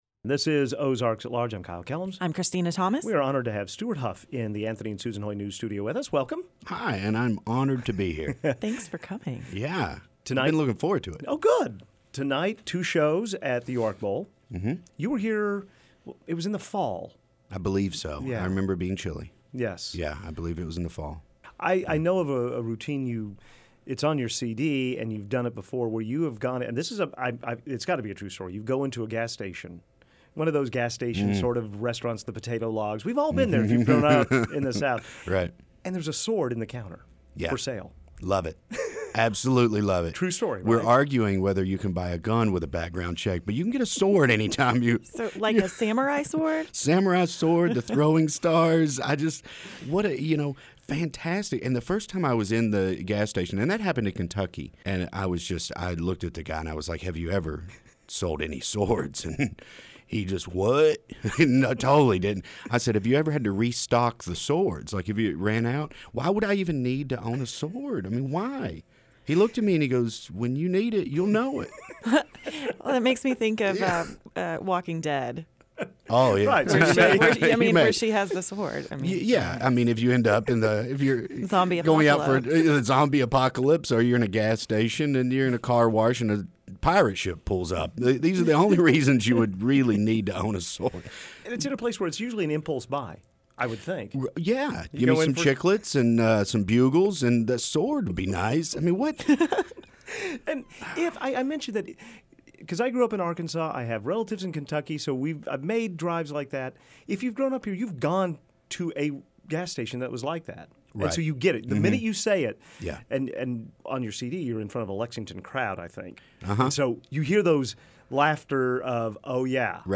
He’ll be at the UARK Bowl for two shows tonight and came to our studio earlier today.